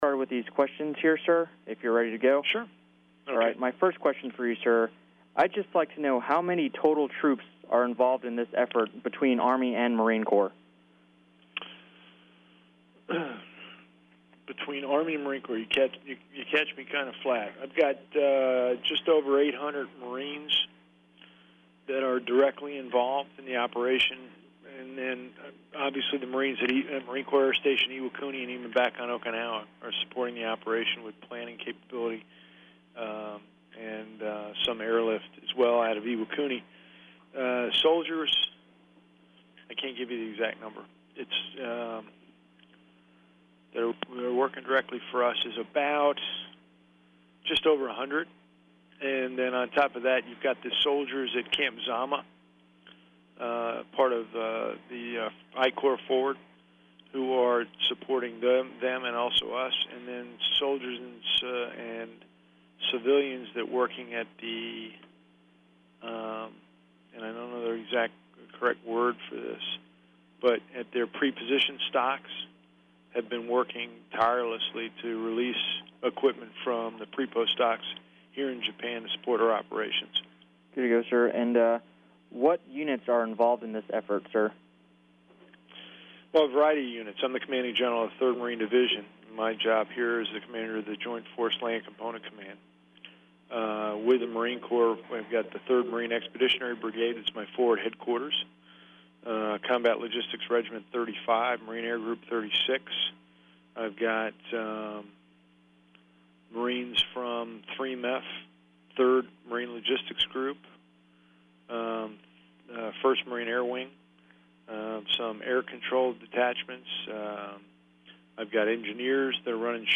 Maj. Gen. Brilakis talks to a Marines TV reporter about the humanitarian relief effort in Japan.